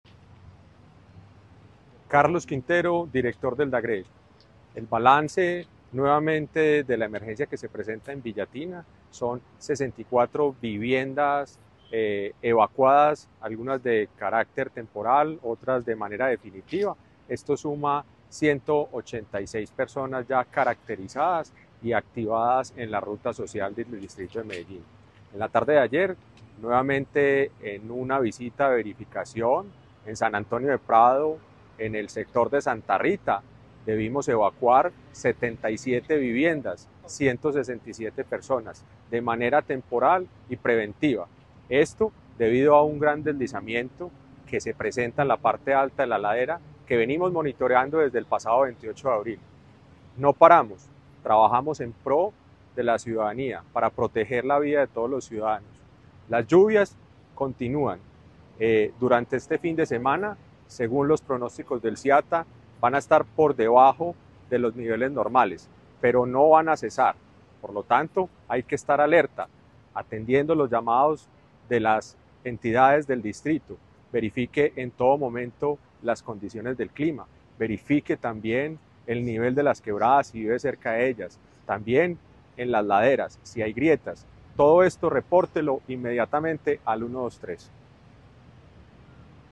Audio Declaraciones del director del DAGRD, Carlos Andrés Quintero Allí, la entidad había realizado, el pasado 28 de abril, una primera labor de identificación para prevenir posibles deslizamientos.
Audio-Declaraciones-del-director-del-DAGRD-Carlos-Andres-Quintero.mp3